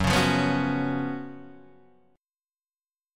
F#M7sus4 chord